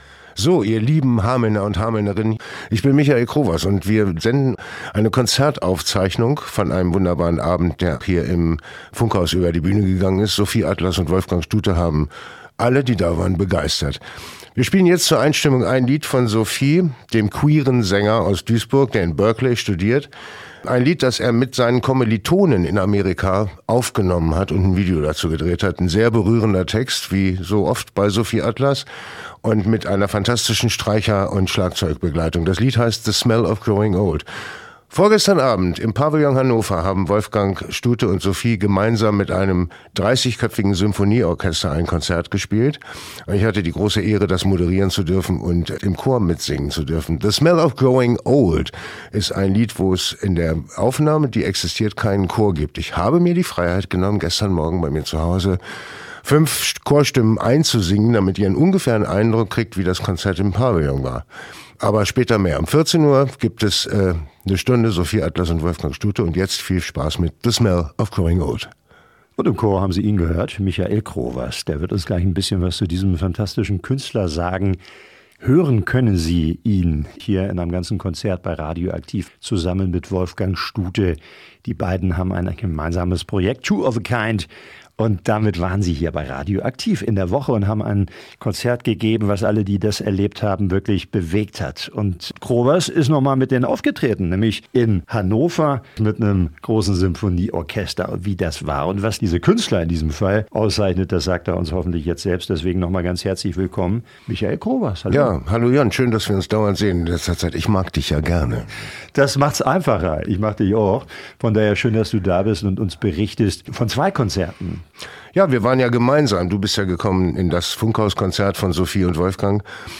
Radio-Konzert